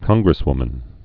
(kŏnggrĭs-wmən)